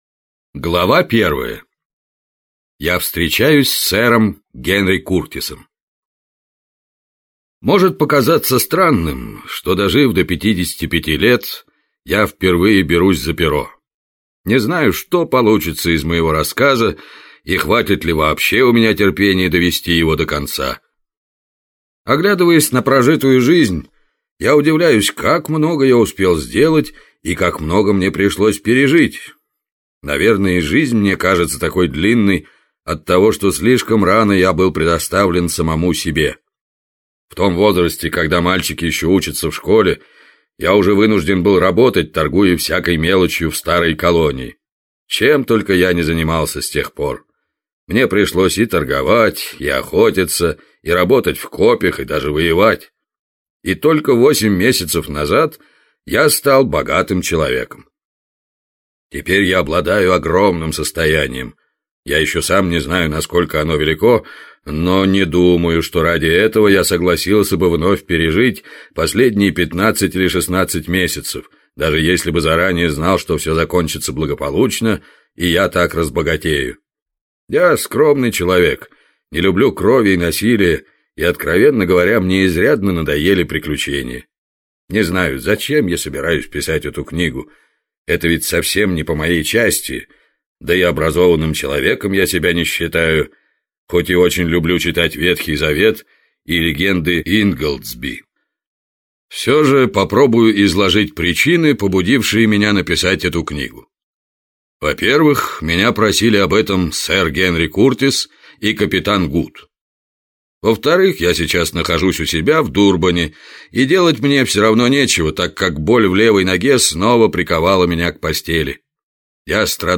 Аудиокнига Копи царя Соломона | Библиотека аудиокниг